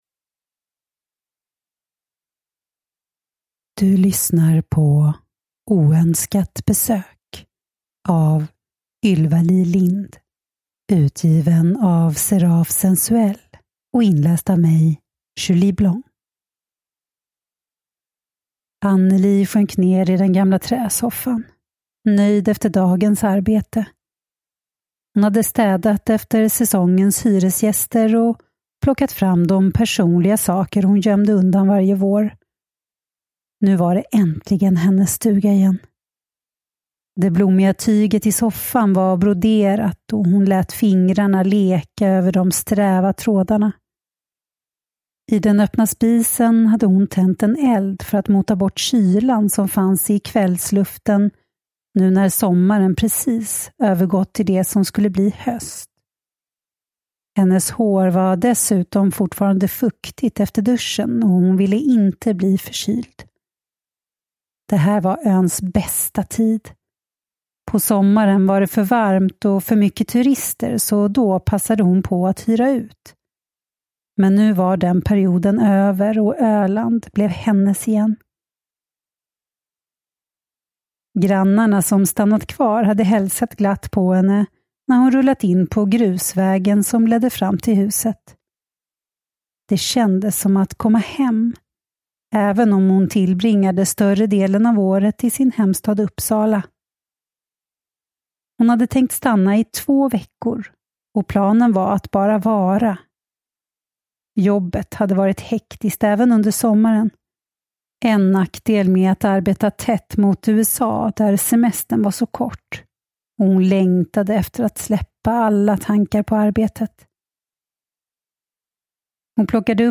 Oönskat besök (ljudbok) av Ylva-Li Lindh